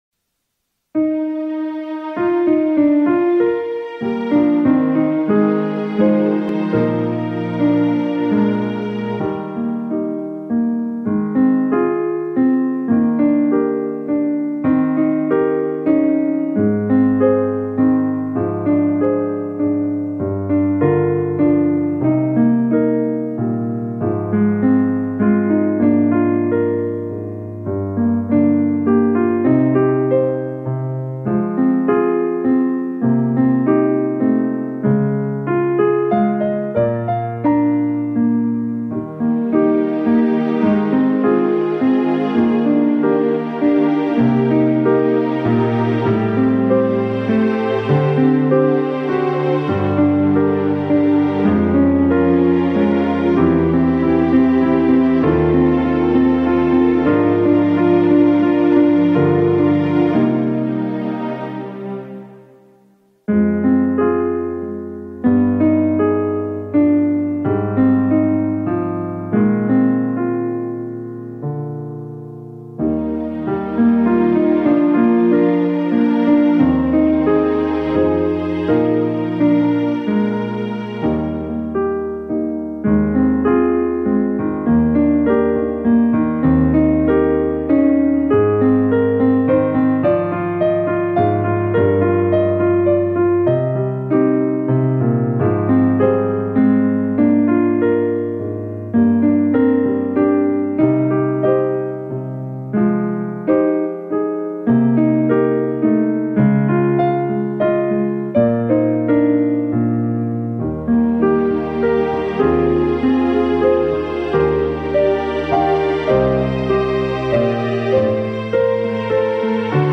N.B. Het zijn thuisopnames, dus verwacht geen uitgebalanceerde opname!
Meestal is van de liedjes alleen het 1e couplet ingezongen
A Clare Benediction - John Rutter PIANO ACCOMPANIMENT.mp3